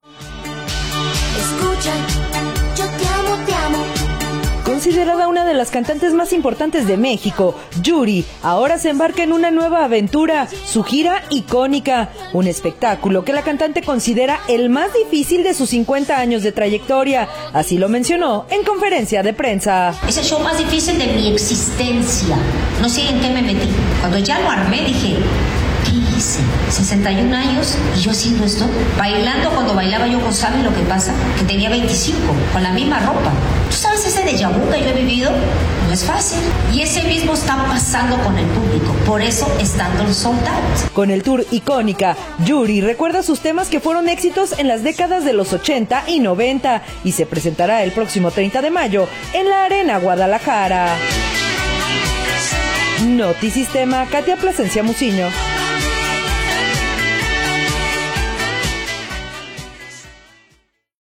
Considerada una de las cantantes más importantes de México, Yuri ahora se embarca en una nueva aventura, su gira “Icónica”, un espectáculo que la cantante considera el más difícil de sus 50 años de trayectoria, así lo mencionó en conferencia de prensa.